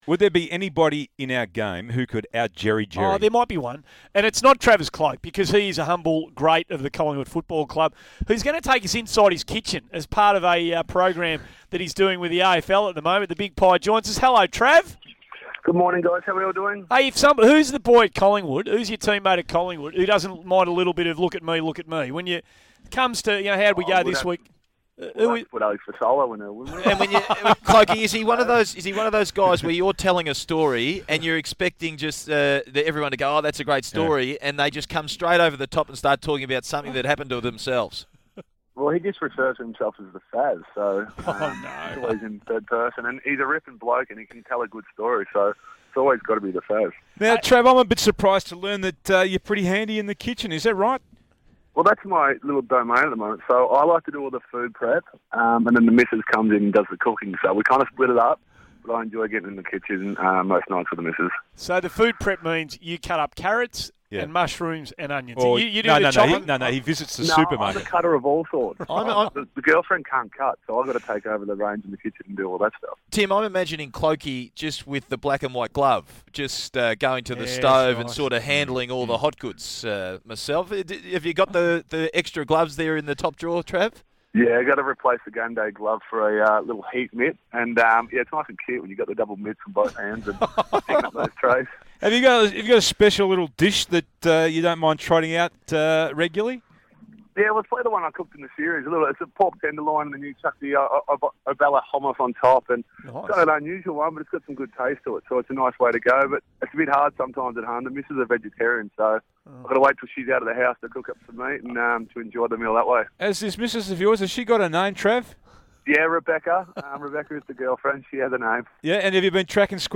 Listen to Collingwood forward Travis Cloke join Tim Watson